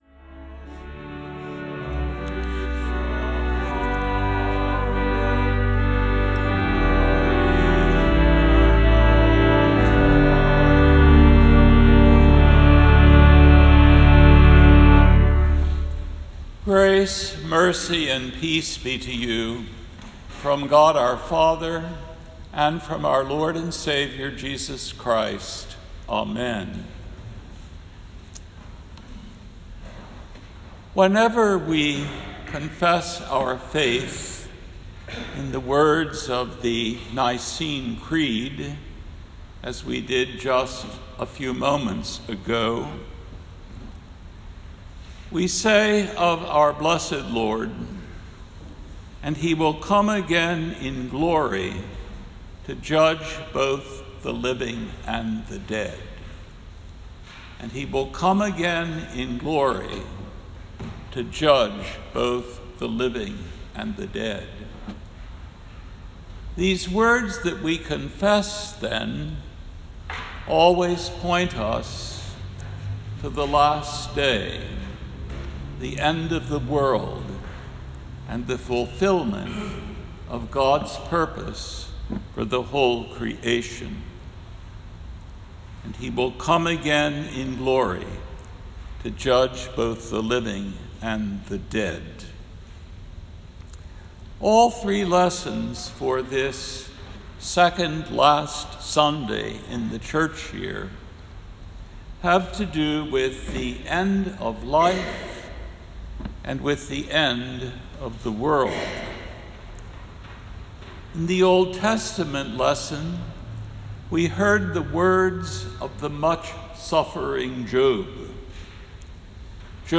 The Second Sunday in Advent December 6, 2020 AD